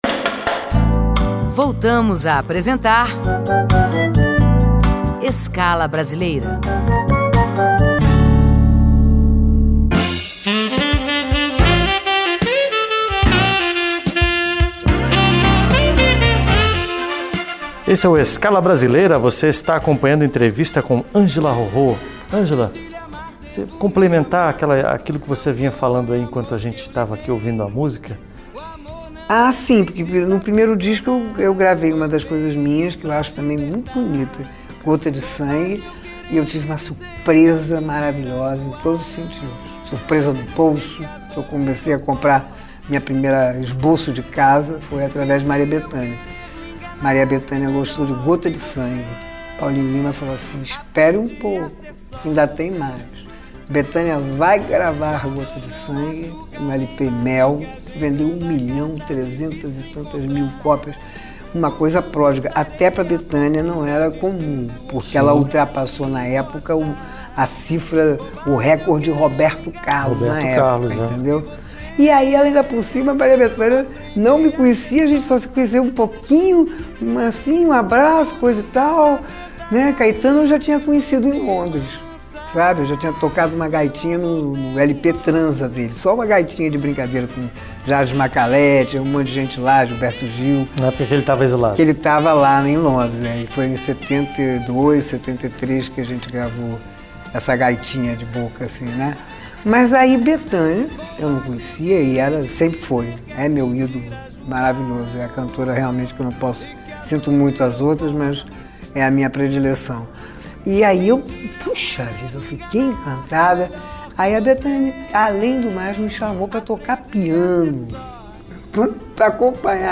Música e entrevistas com artistas brasileiros